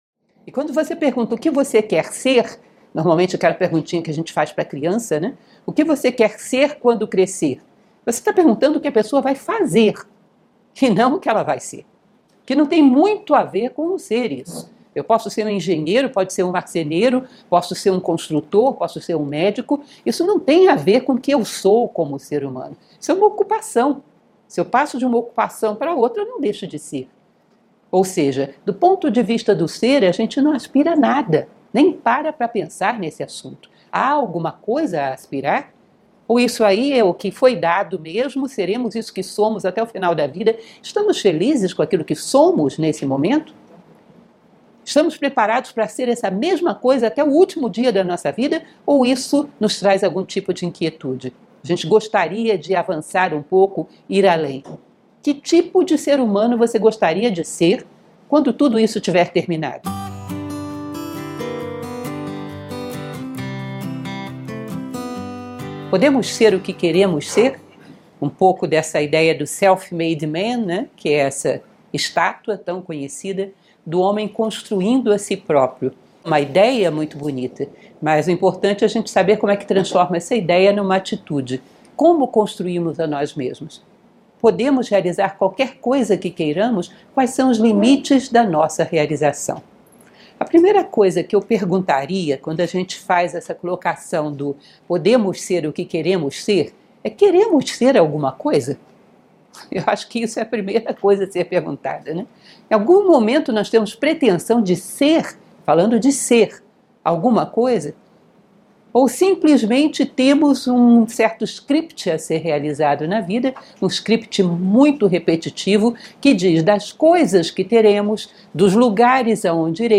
Palestras Filosóficas Nova Acrópole